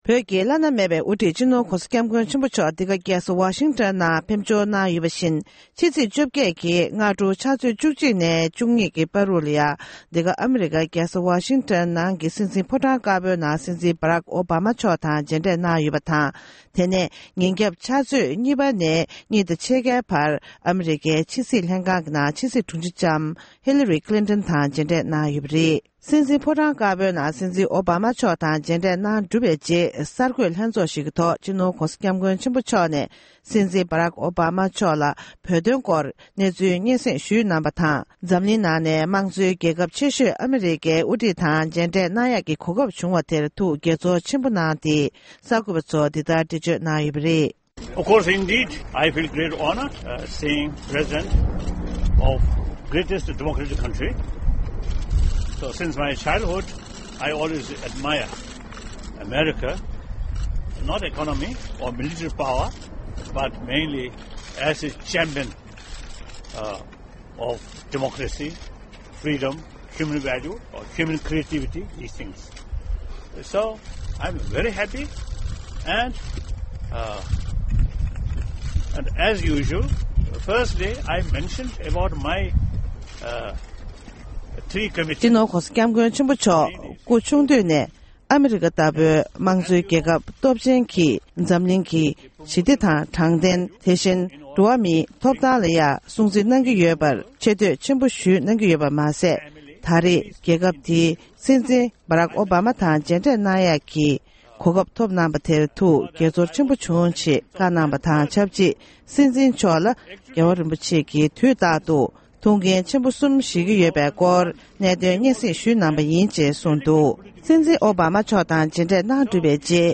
གསར་འགོད་ལྷན་ཚོགས།